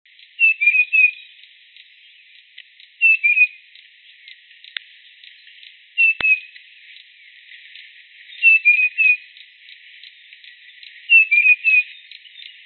s5-1-2012東埔灰鷽1.mp3
灰鷽 Pyrrhula erythaca owstoni
錄音地點 南投縣 信義鄉 東埔
5 錄音環境 灌木叢 發聲個體 行為描述 鳥叫 錄音器材 錄音: 廠牌 Denon Portable IC Recorder 型號 DN-F20R 收音: 廠牌 Sennheiser 型號 ME 67 標籤/關鍵字 備註說明 MP3檔案 s5-1-2012東埔灰鷽1.mp3